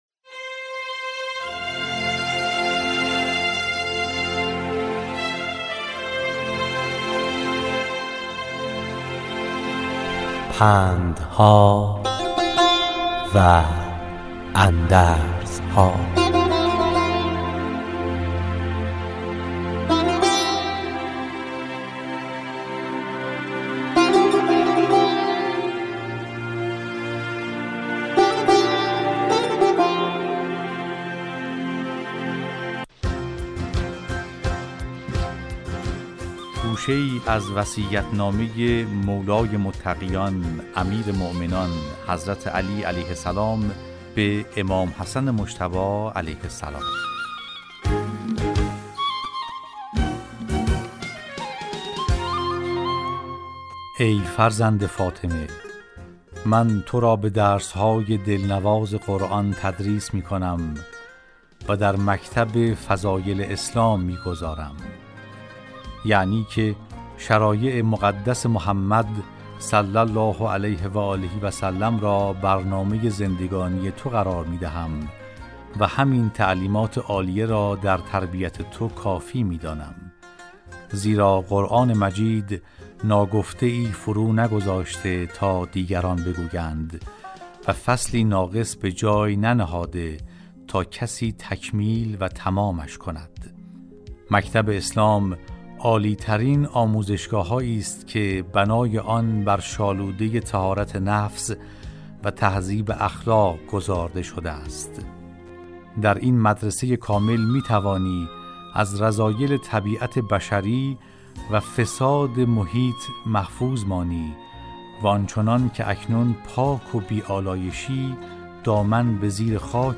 در برنامه " پندها و اندرزها"، راوی برای شنوندگان عزیز صدای خراسان، حکایت های پندآموزی را روایت می کند .